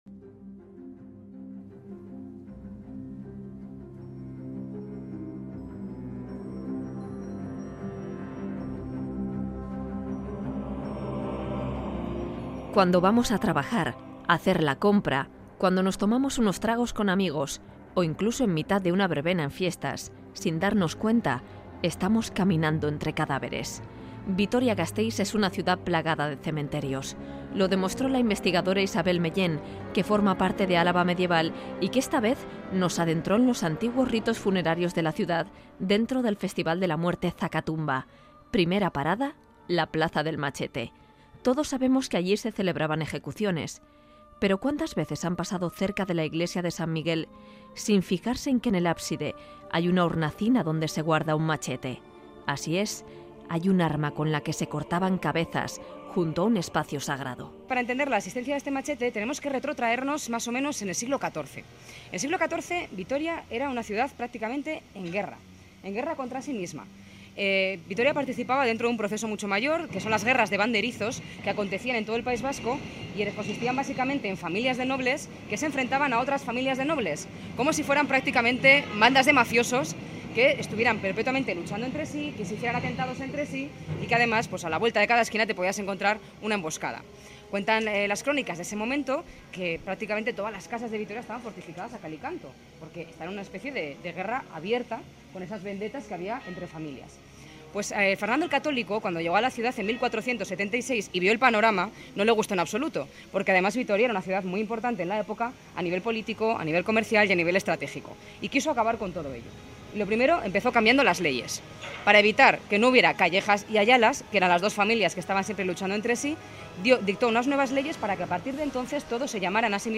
REPORTAJE: Vitoria-Gasteiz, repleta de antiguos cementerios